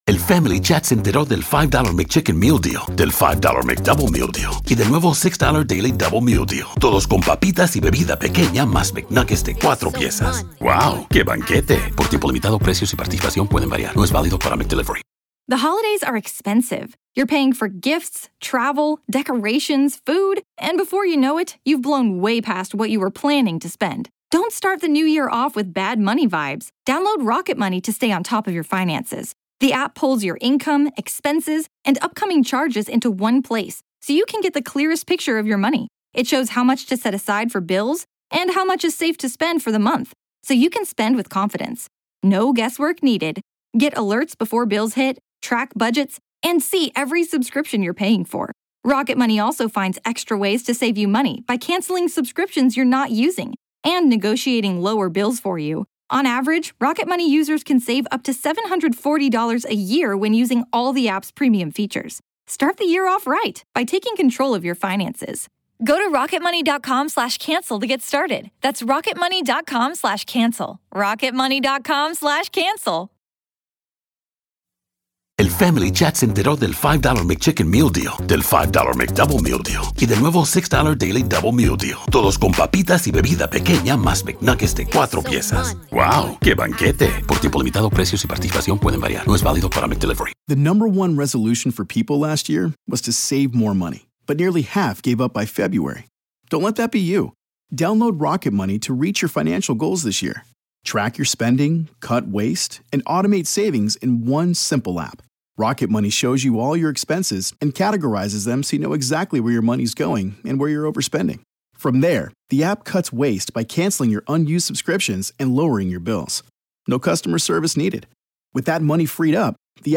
Full Interview With Suspected Gilgo Beach Killer Rex Heuermann